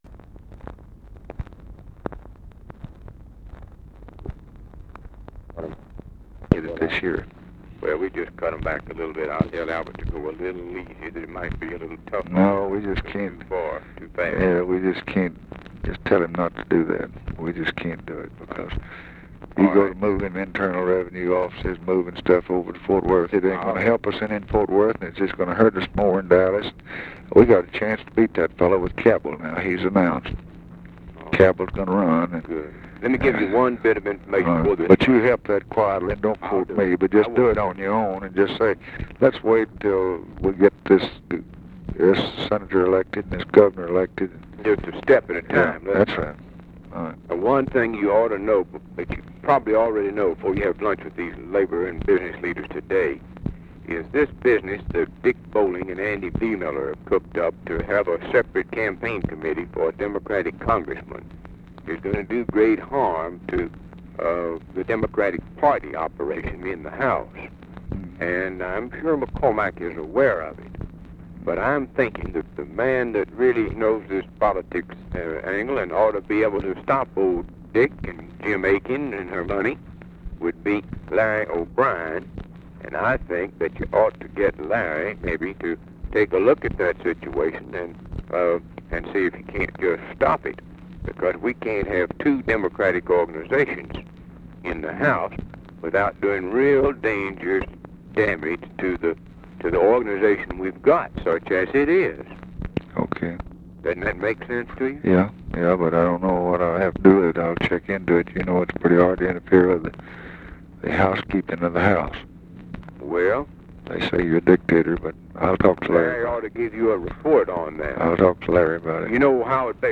Conversation with JACK BROOKS, January 7, 1964
Secret White House Tapes